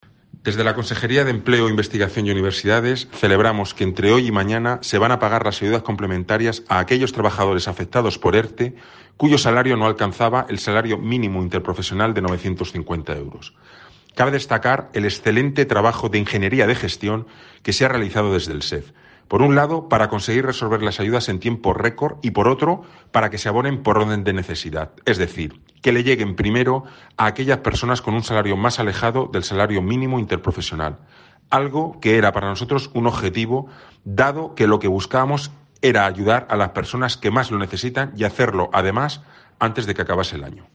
Miguel Motas, consejero de Empleo